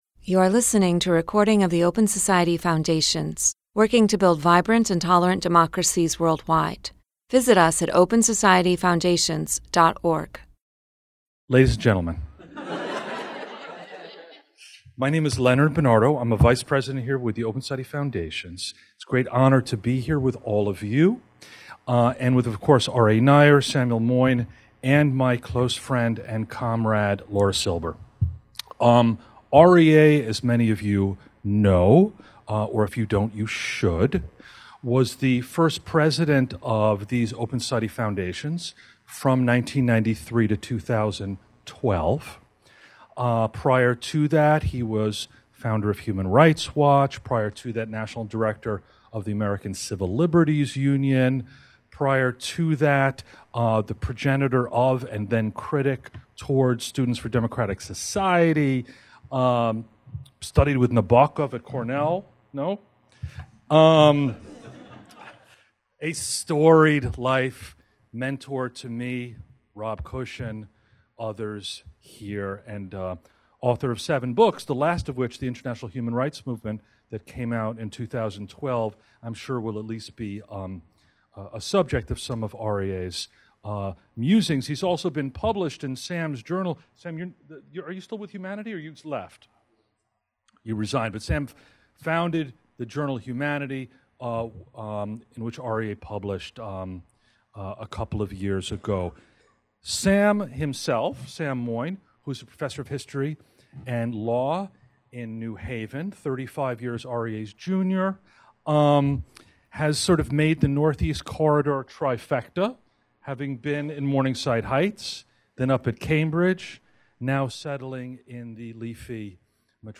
Samuel Moyn and Aryeh Neier debate the human rights movement’s response to economic inequality: is this a fight that must be taken on, or is it one that rights can’t help to win?
In this live-streamed event, Samuel Moyn and Aryeh Neier will tackle these thorny questions and more in what promises to be a powerful discussion about the past and future direction of the human rights movement.